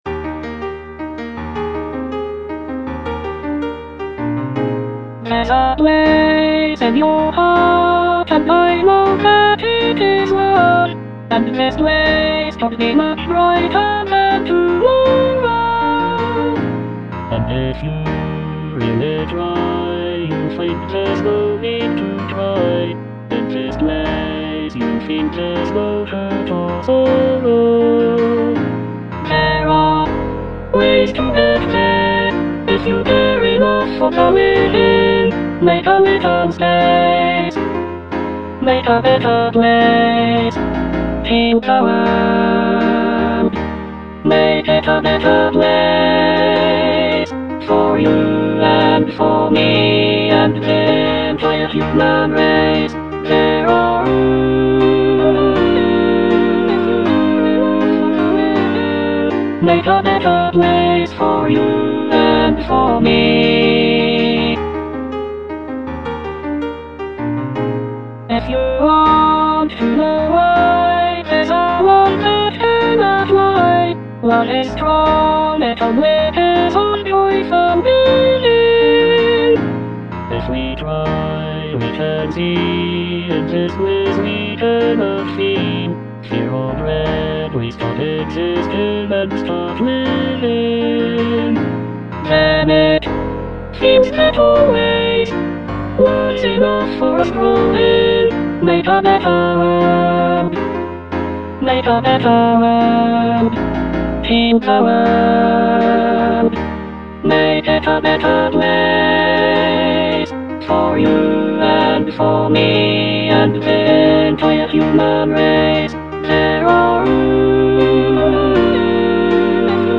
Alto I, small group (Emphasised voice and other voices)